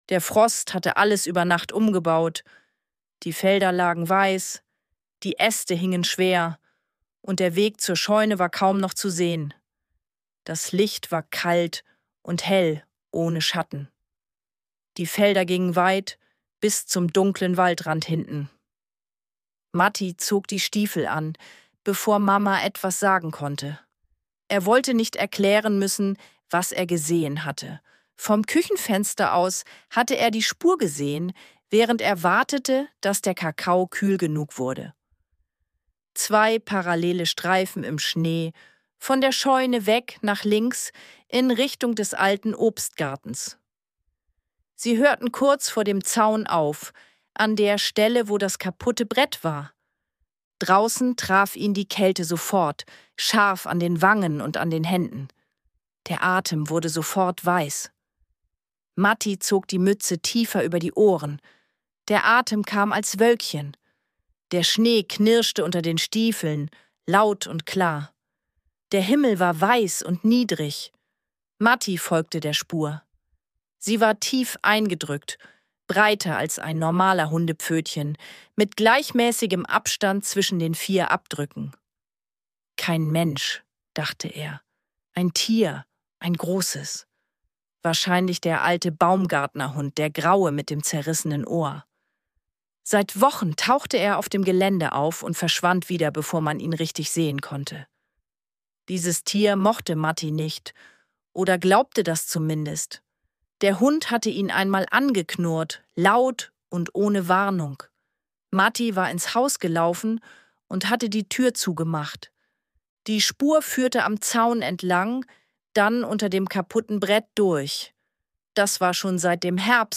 Ruhige Kindergeschichten zum Anhören
Ruhig erzählt, klar und nah –